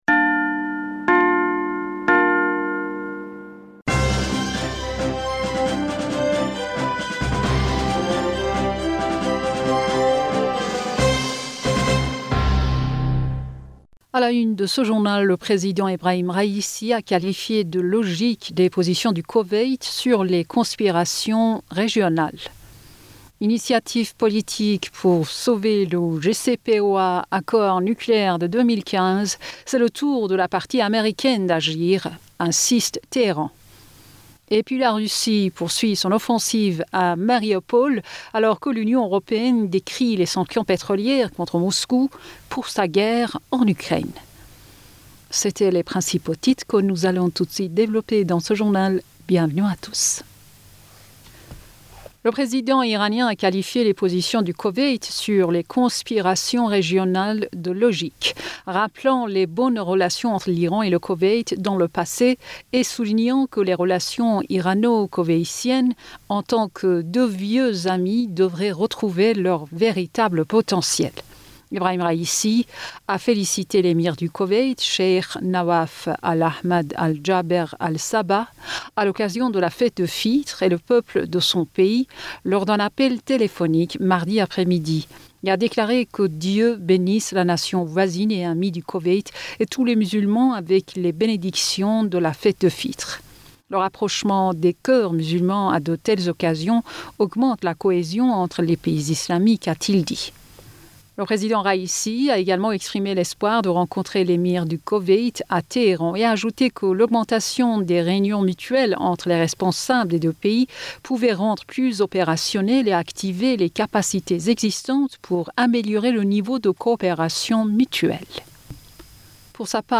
Bulletin d'information Du 04 Mai 2022